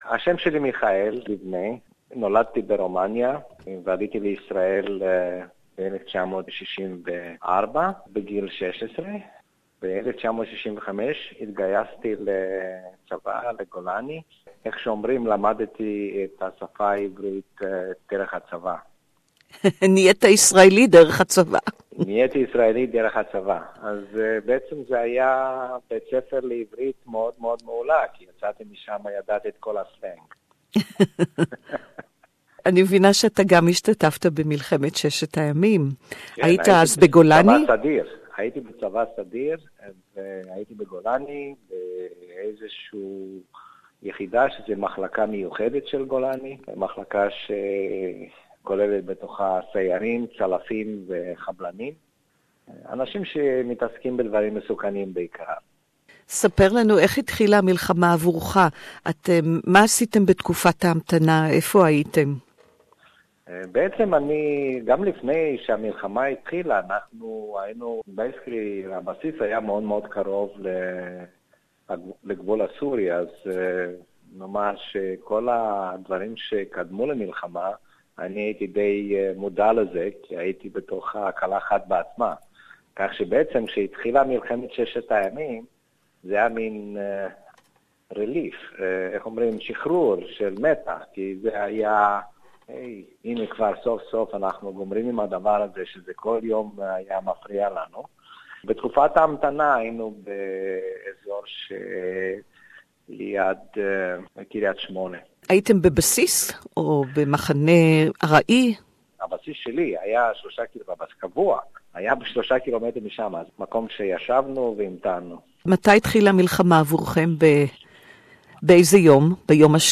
who took part in the Six- Day War as a young soldier in the IDF This interview is in Hebrew